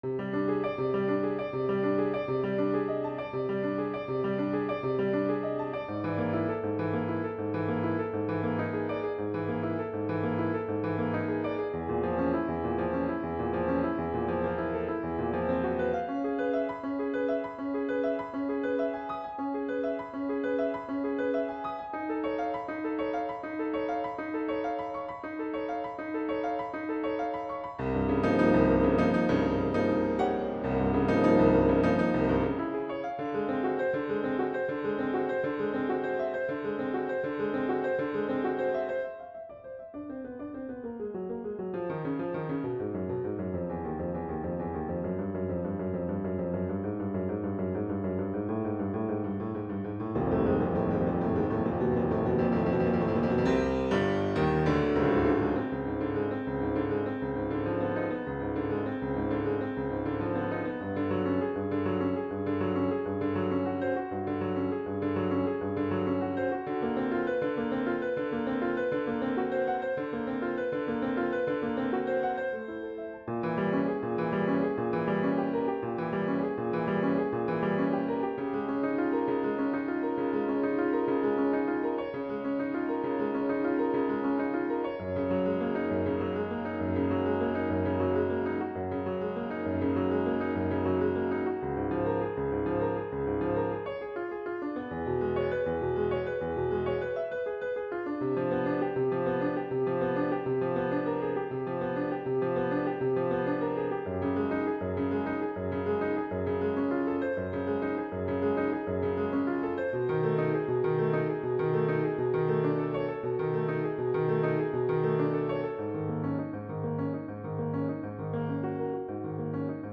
Etude No. 1, "Lavish Waves" - Piano Music, Solo Keyboard
I quite like the harmonic language in this piece, so I might steal it for later pieces... maybe not so arpeggiated, though.